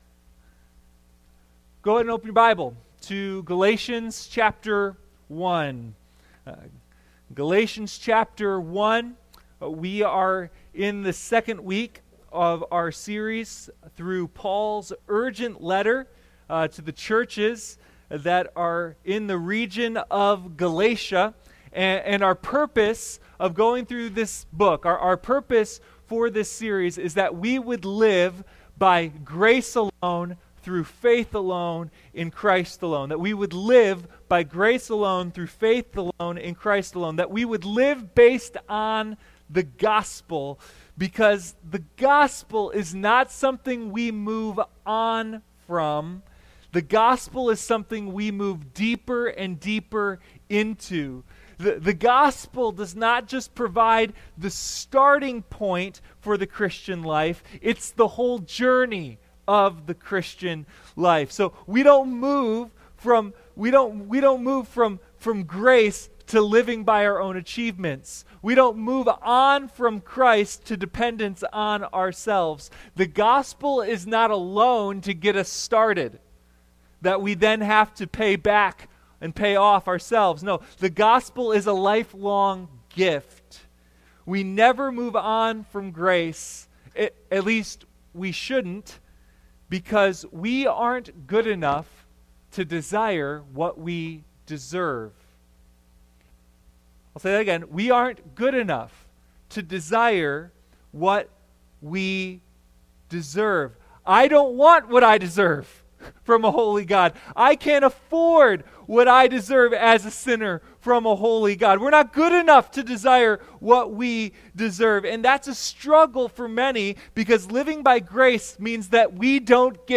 Sunday Morning No Other Gospel: A Study in Galatians